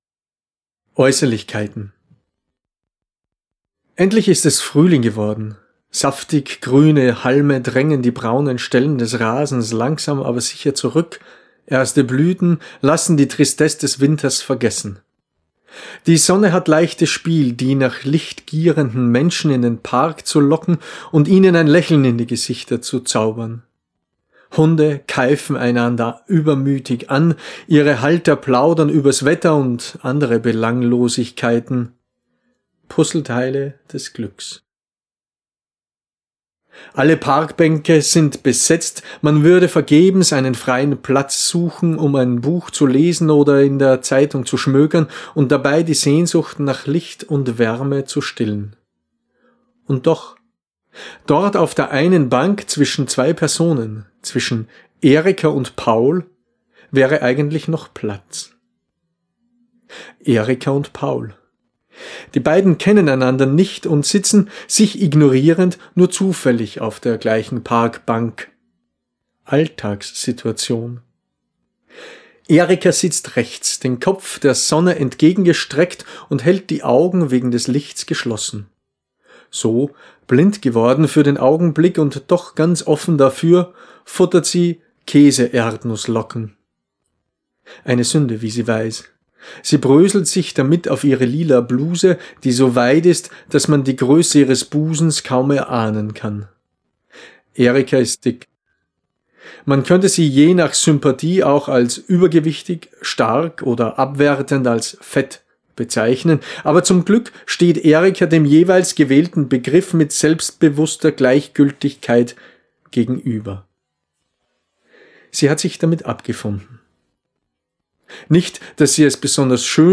Erzählung gelesen von